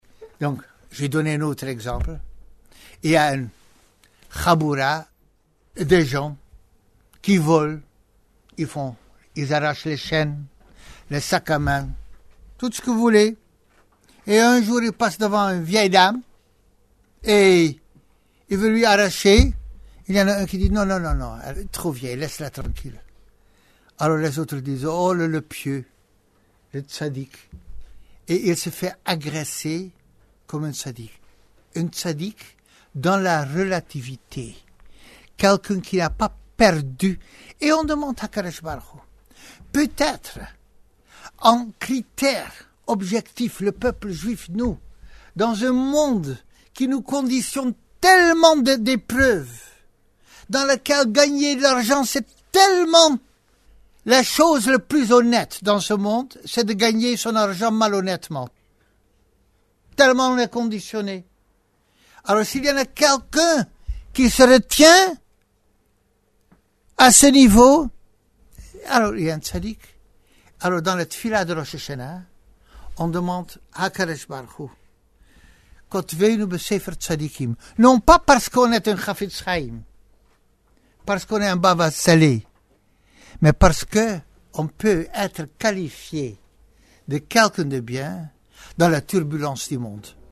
Nous sommes durant les Asséreth Yemeï Teshouva, les 10 jours de Teshouva qui vont de Rosh Hashana à Yom Kippour.